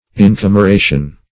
Incameration \In*cam`er*a"tion\, n.